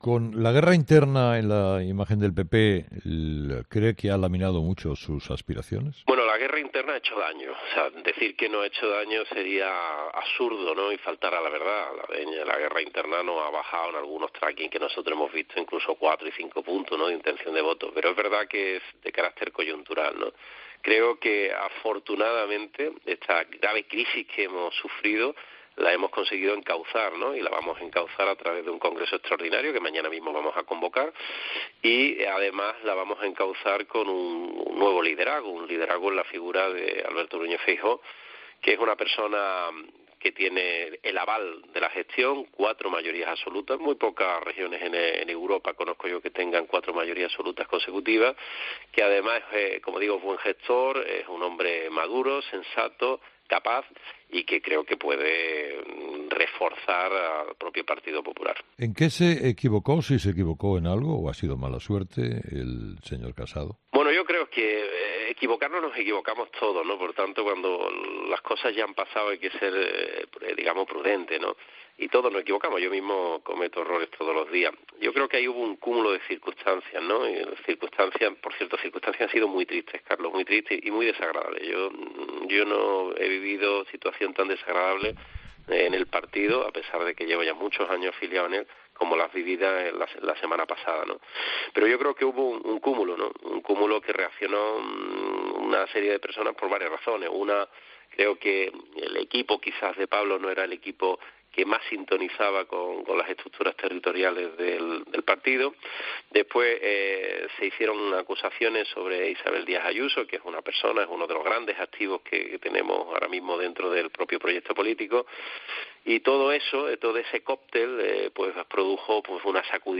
Herrera entrevista a Moreno Bonilla: "Feijoó es el gran emblema del PP".